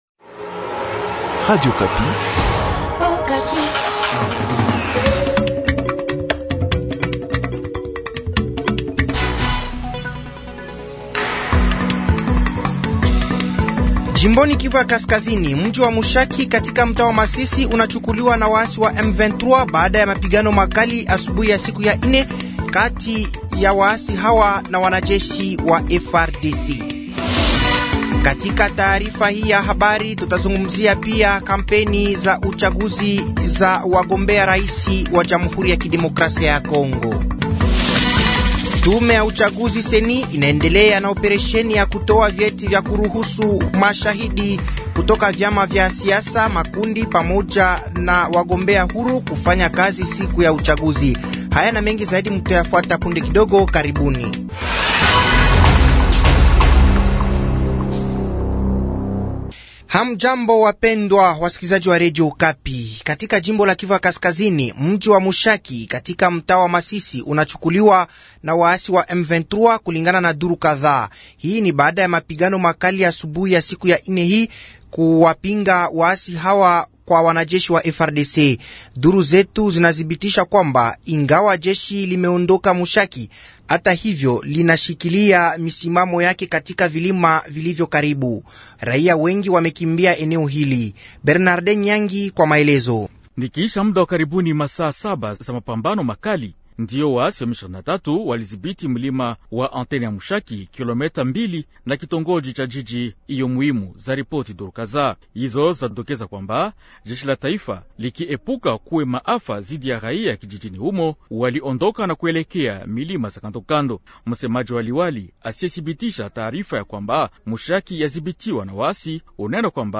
Journal Soir
Habari za siku ya inne jioni tarehe 07/12/2023